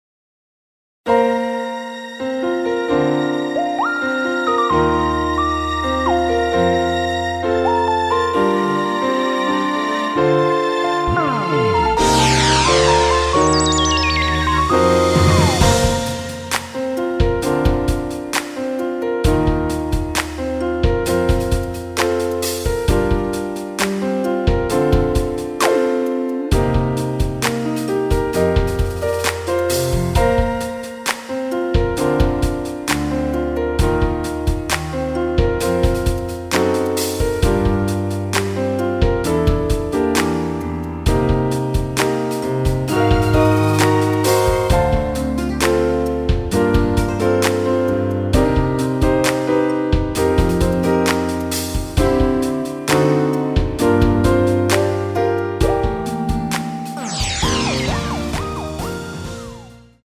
Bb
앞부분30초, 뒷부분30초씩 편집해서 올려 드리고 있습니다.
중간에 음이 끈어지고 다시 나오는 이유는
위처럼 미리듣기를 만들어서 그렇습니다.